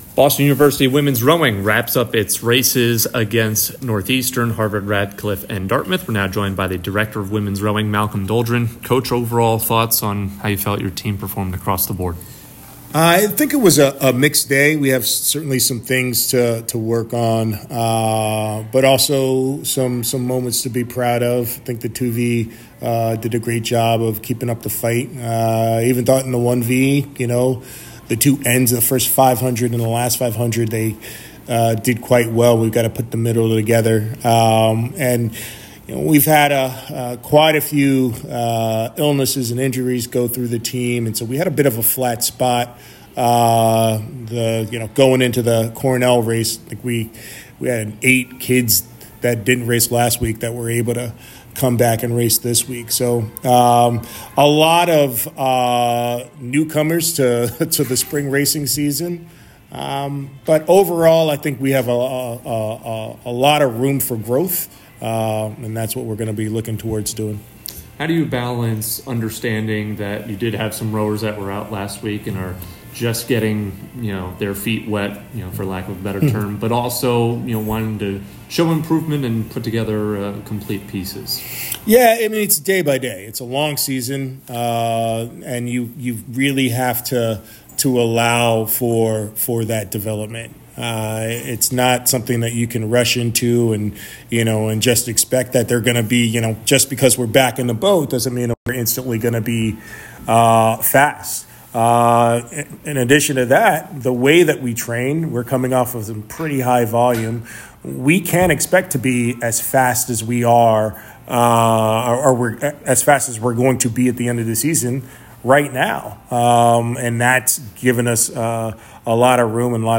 Women's Rowing / Radcliffe, Northeastern, and Dartmouth Postrace Interview (4-6-24)